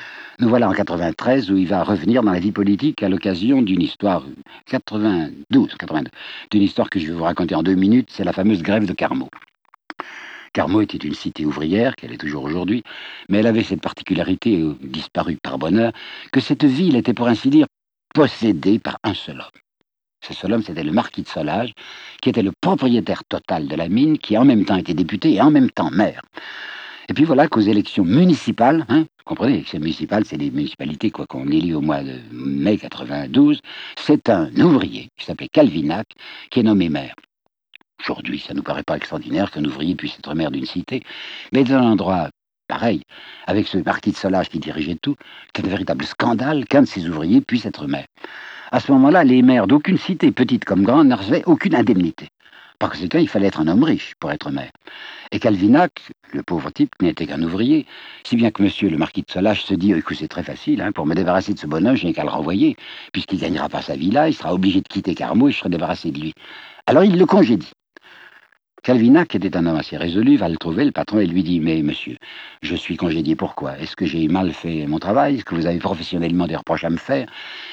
Conférence audio. CD + transcription livre